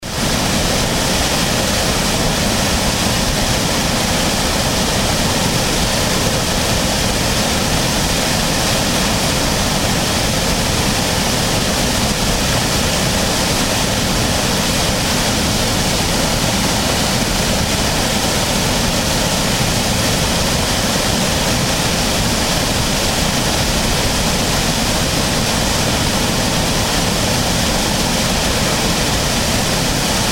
West Creek Falls
westcreekfalls.mp3